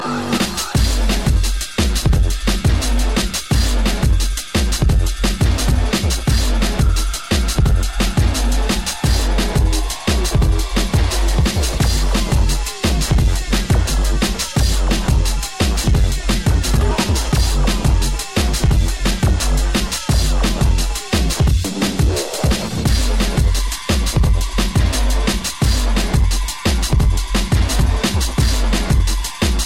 TOP >Vinyl >Drum & Bass / Jungle
TOP > HARD / TECH